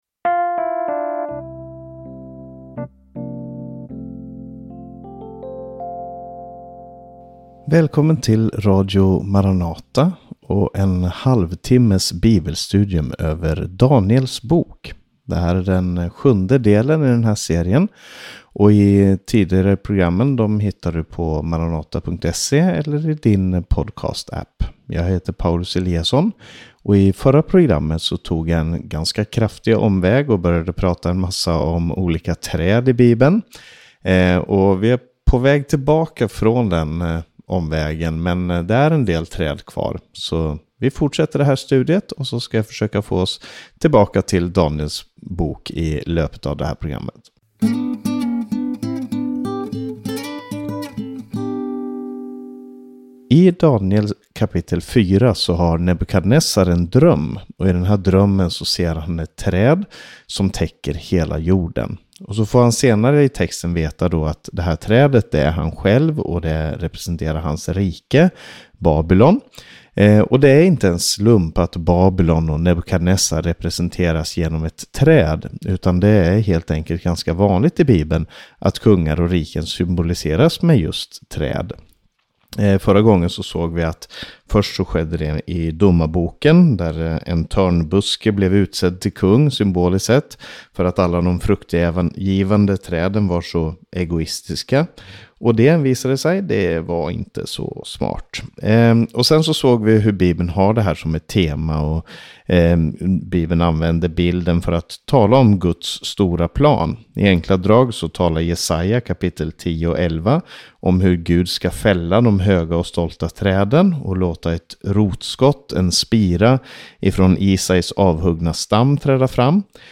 Ett studium i Daniel 4, som talar om en märklig dröm av kung Nebukadnessar. Det är träd som sträcker sig mot himmelen, och heliga väktare som dömer kungar.